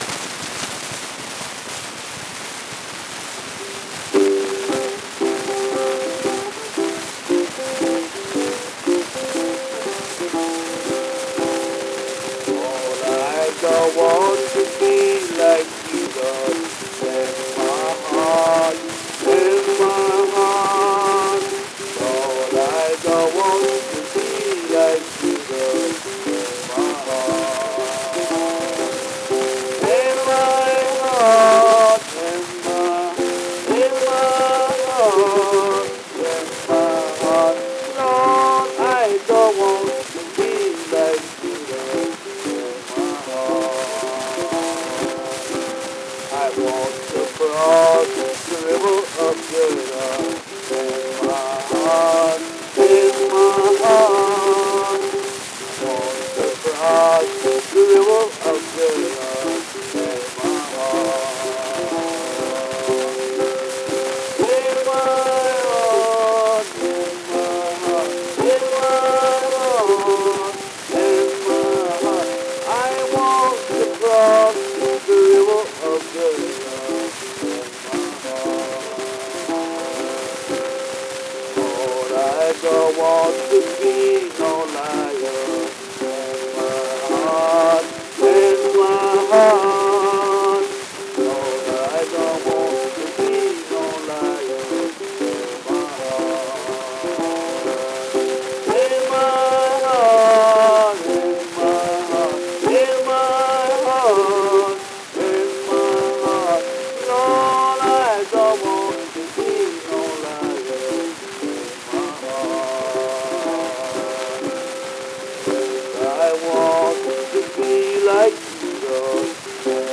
Paramount 12386 was recorded at Rodeheaver Recording Laboratories in Chicago, Illinois, in late December of 1925 or early January of the following year, and was released around October 2, 1926.
Jefferson delivers a lighter, but no less effective performance of the traditional Negro spiritual “I Want to Be Like Jesus in My Heart” on the flip.